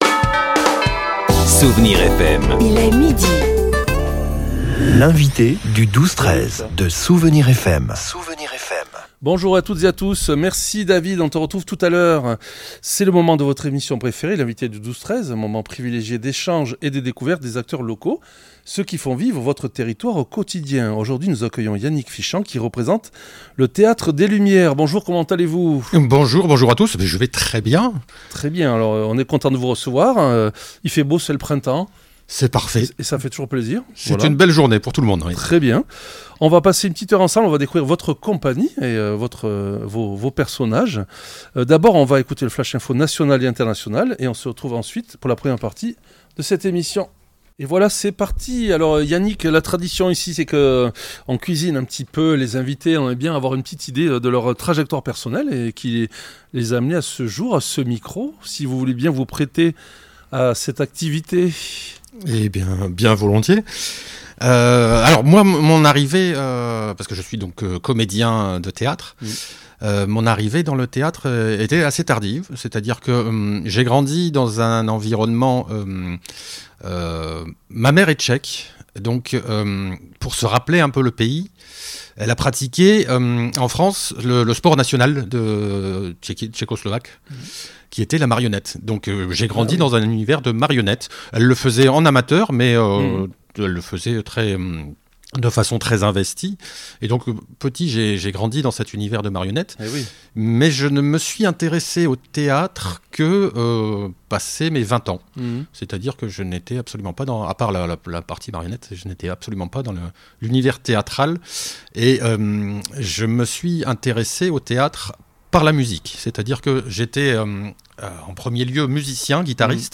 L'entretien a permis de plonger dans l'univers fascinant de la Commedia dell'arte, pilier de la compagnie avec son célèbre "Festival de Tréteaux" créé en 2011.